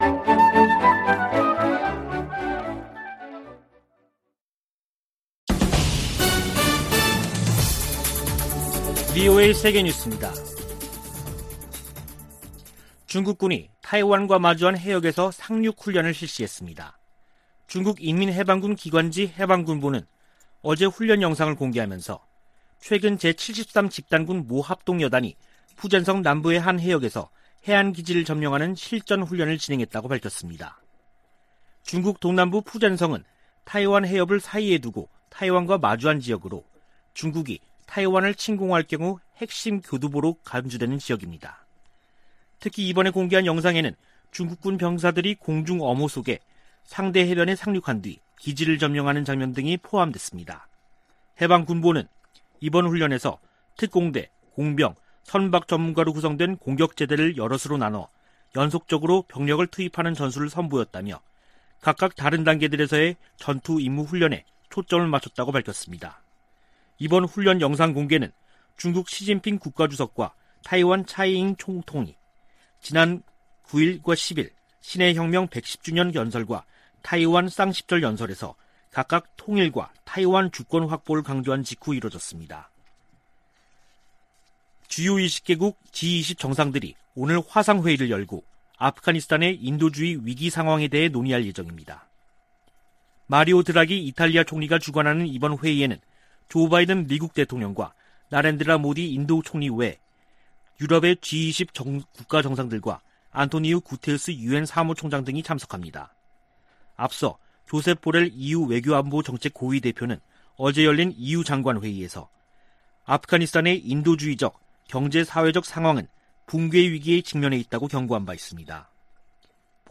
VOA 한국어 간판 뉴스 프로그램 '뉴스 투데이', 2021년 10월 12일 3부 방송입니다. 김정은 북한 국무위원장은 미국과 한국이 주적이 아니라면서도 핵무력 증강 지속 의지를 확인했습니다. 미국 정부의 대북정책을 지지하는 미국인이 작년보다 감소한 조사 결과가 나왔습니다. 옥스포드 사전에 '오빠' 등 한국어 단어 26개가 추가됐습니다.